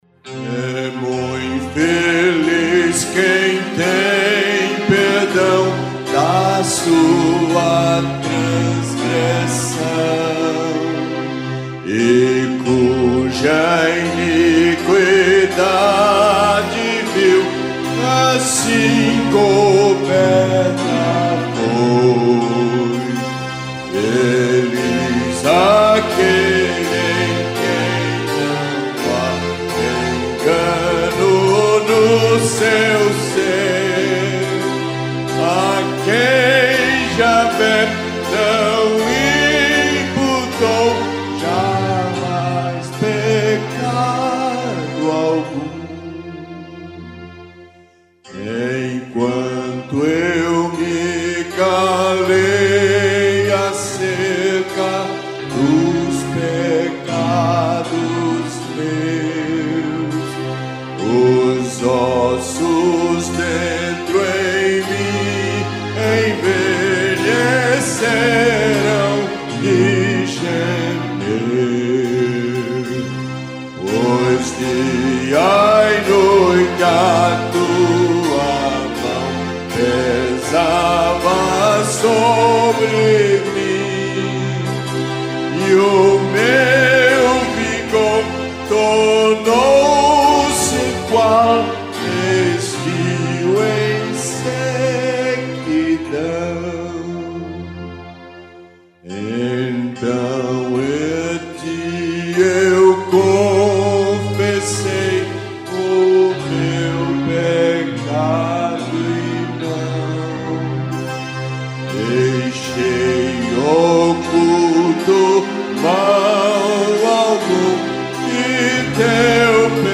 salmo_32B_cantado.mp3